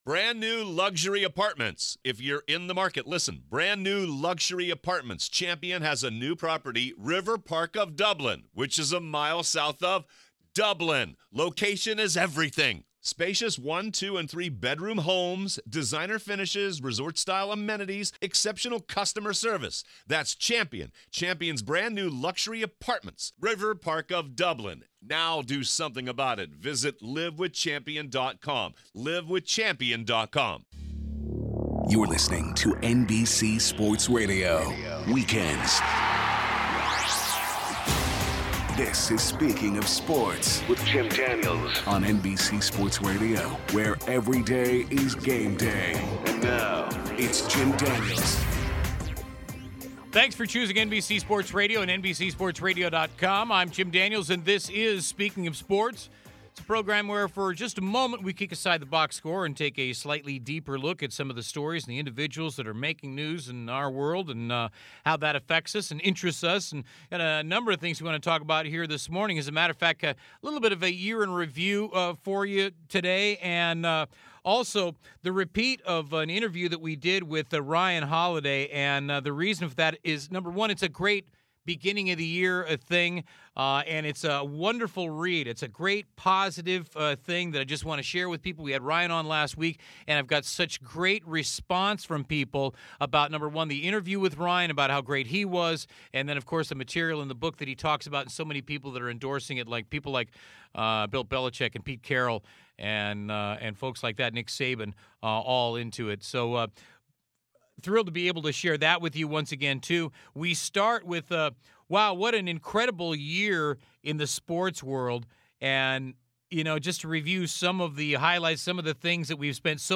Special guest Author Ryan Holiday joins us to talk about his inspirational book, "The Obstacle is the Way", and the people who teach it such as future HOF coaches Bill Belichick and Nick Saban.